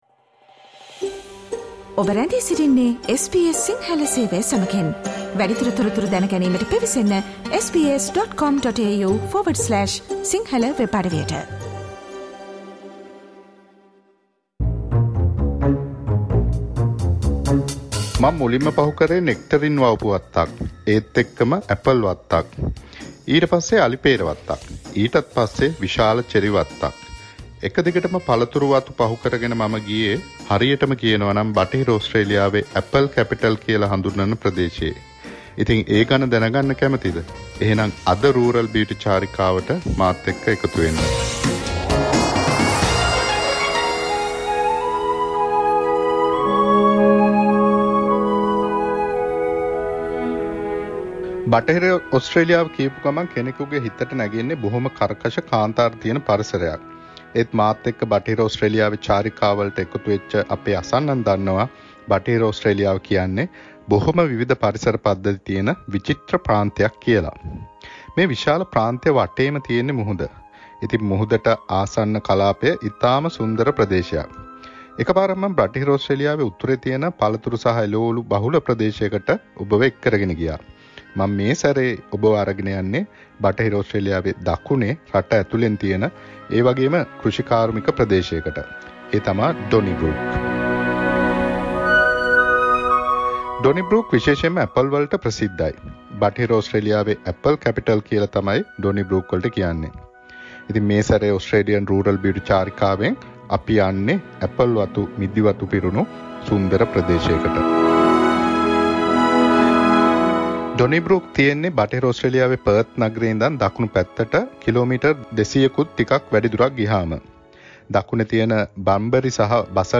Australian Rural Beauty: SBS Sinhala monthly Radio journey to remote Australia